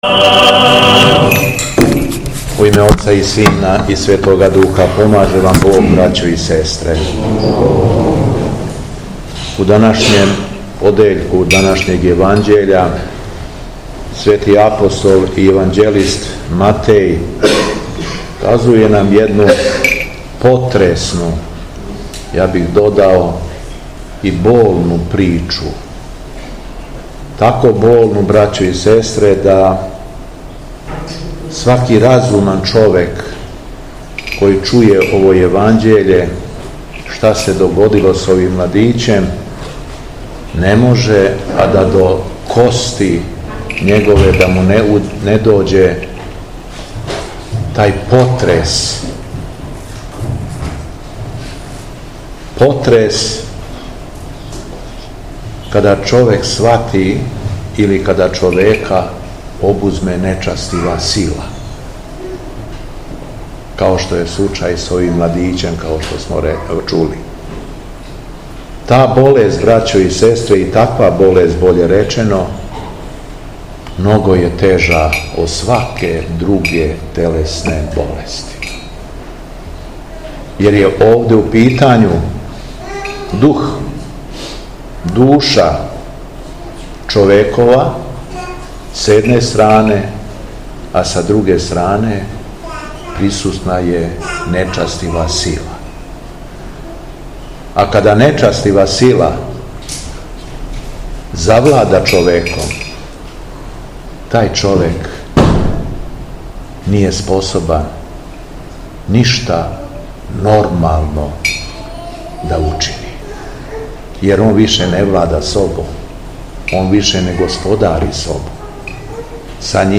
Беседа Његовог Високопреосвештенства Митрополита шумадијског г. Јована
Након прочитаног јеванђеља Митрополит се сабраним верницима обратио беседом: